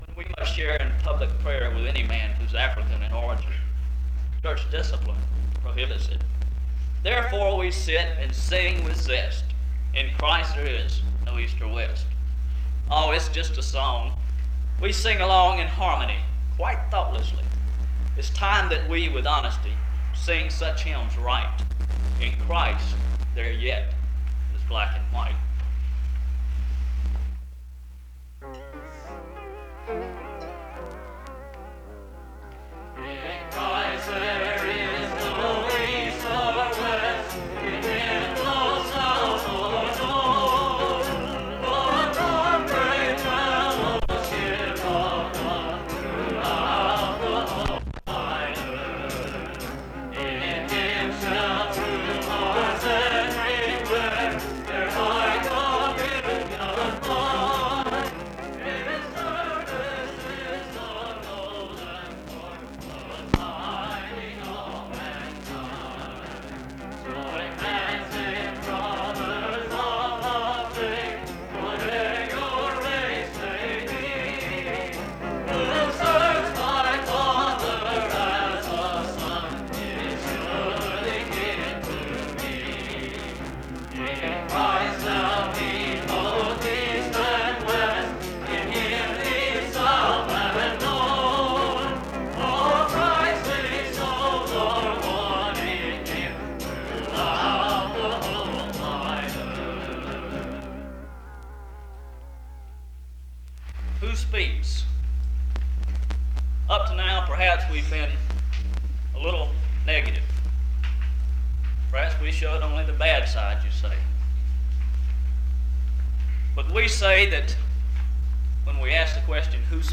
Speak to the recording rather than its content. This service was organized by the Student Coordinating Council.